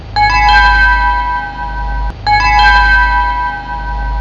I took Hard Disk Sentinel's HDD overheating alarm wav, doubled it & you can download it here & save to My Documents:
It's annoying & hard to miss. :)
Low_Battery_Alarm_Annoying.wav